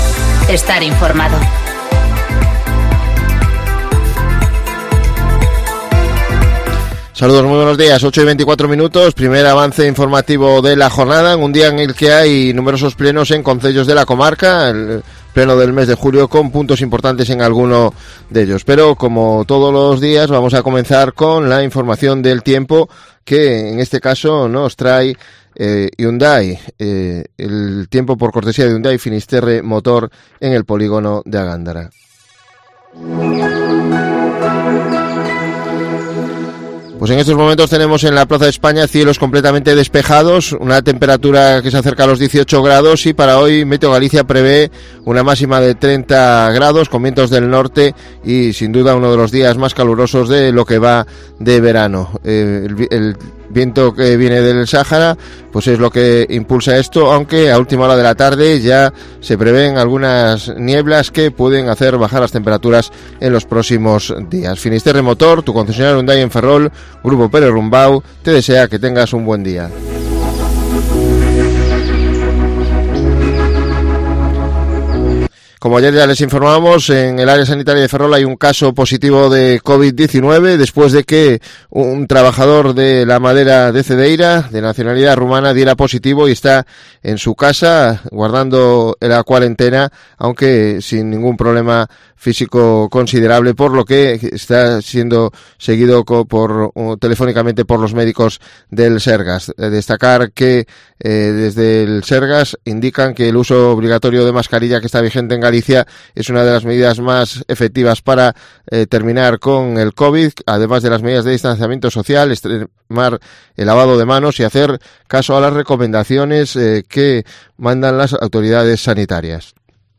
Informativo Cope Ferrol 30/07/2020 (De 08:20 a 08:30 horas)